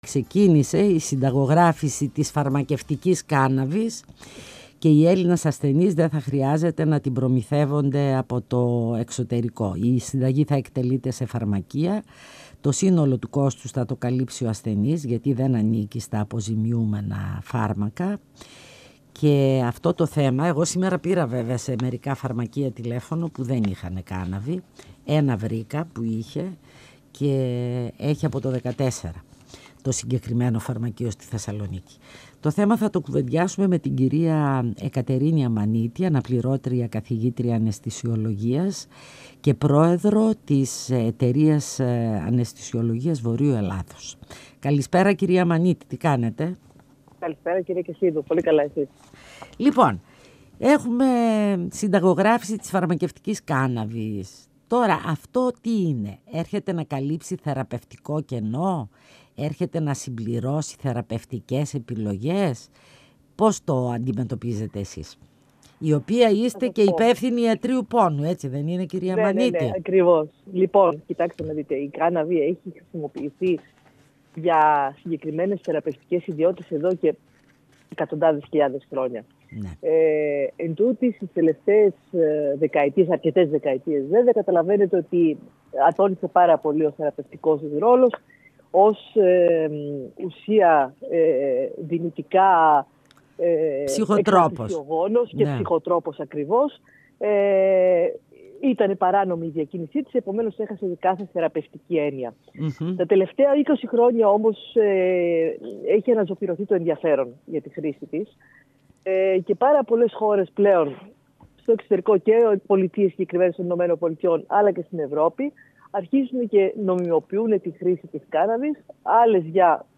Φωνες Πισω απο τη Μασκα Συνεντεύξεις